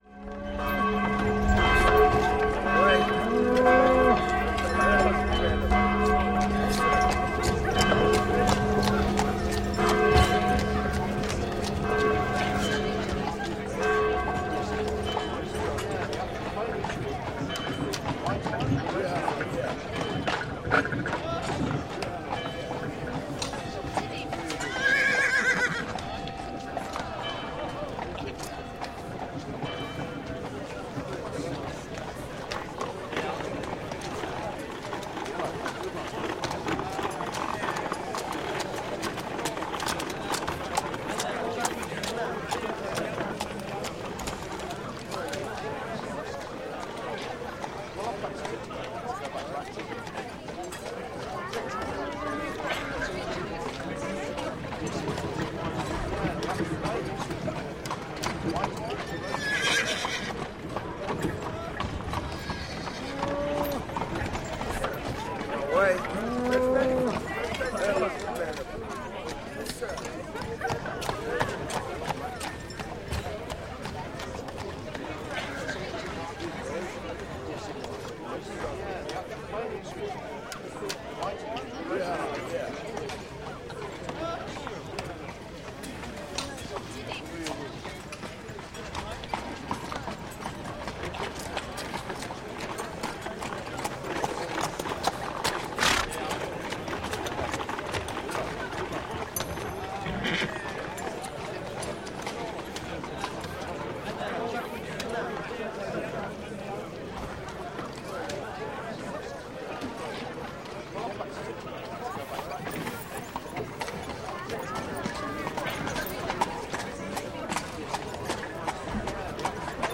Здесь вы услышите гул мостовых, крики торговцев, звон трамваев и другие характерные шумы ушедших эпох.
Старый город - Есть такой вариант (звуки города в 19 или 20 веке)